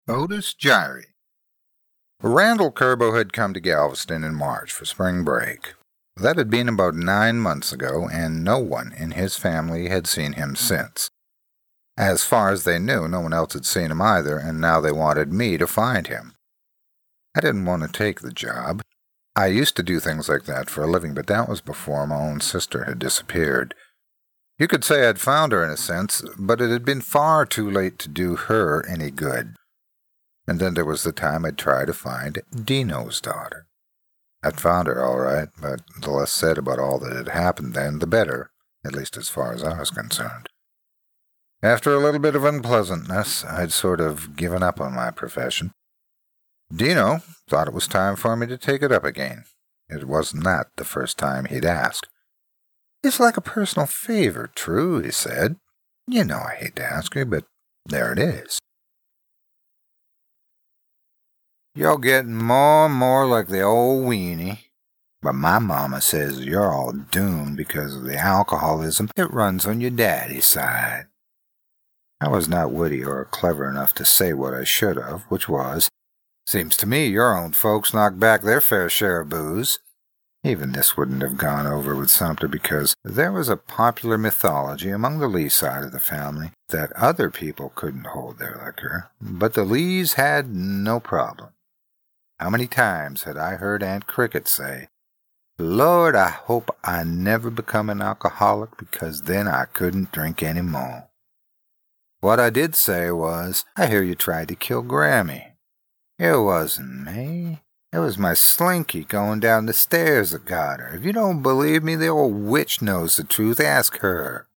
middle west
Sprechprobe: Sonstiges (Muttersprache):